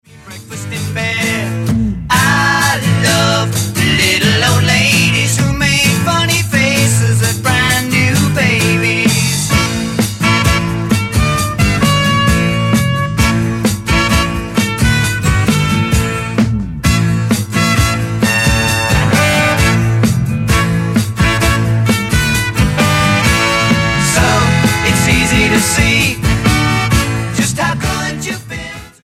STYLE: Jesus Music